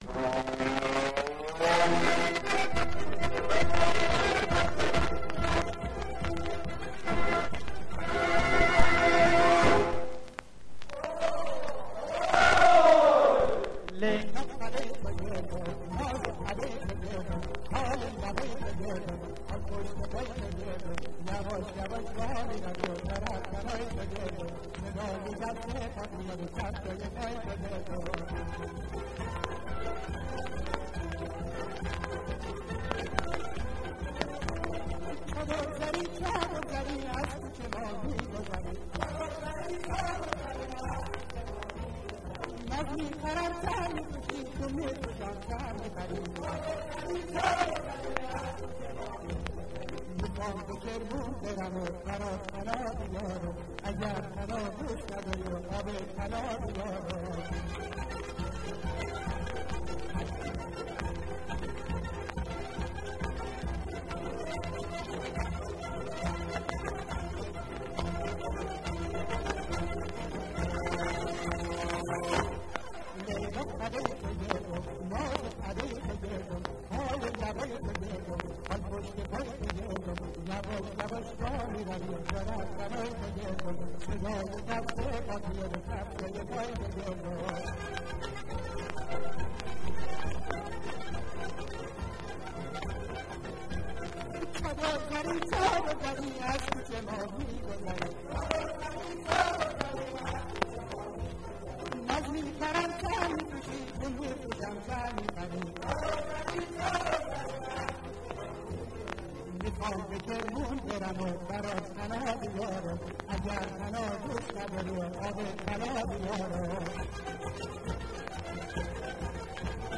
در مقام شور